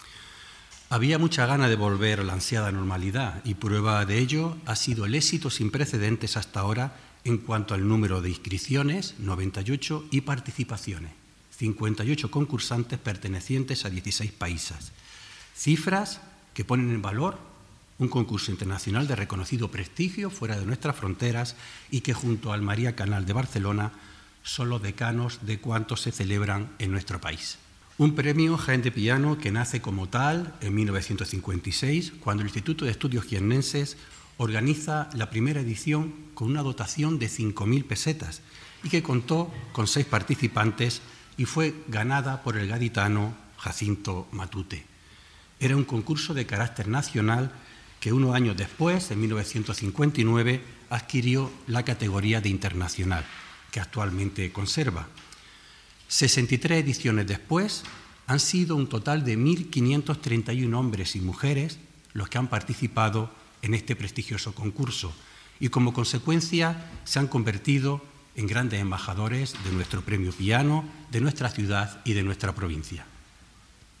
Declaraciones en audio de las autoridades